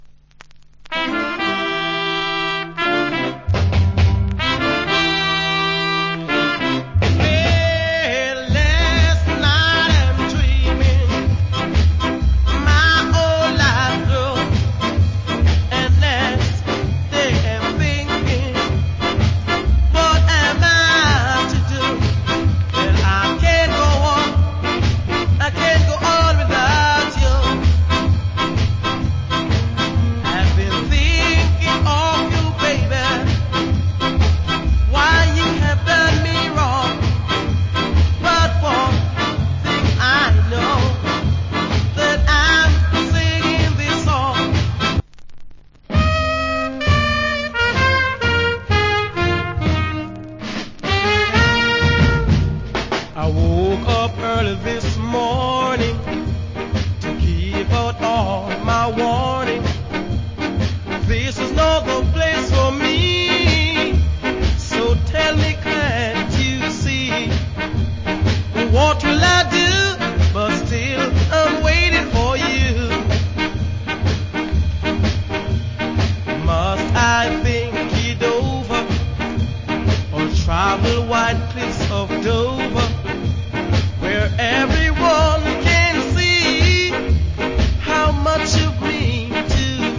Good Ska Vocal.